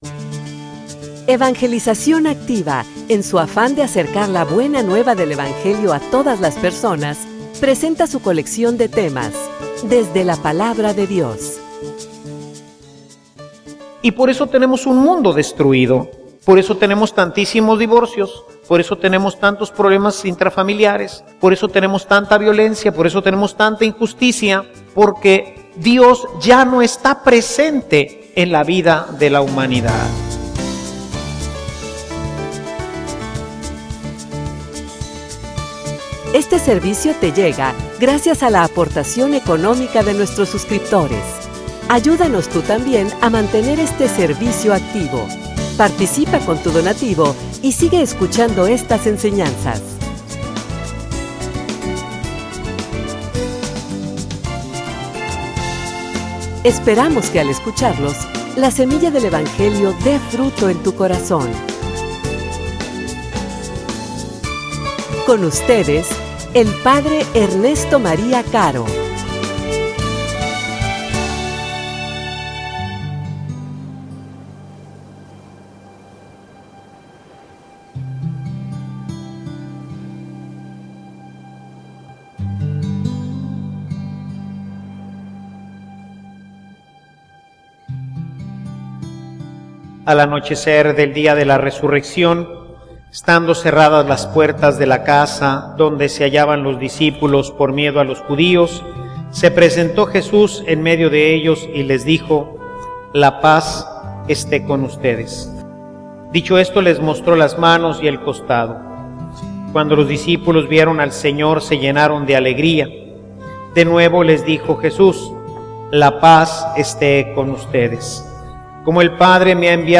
homilia_Como_el_Padre_me_envio_asi_los_envio_yo.mp3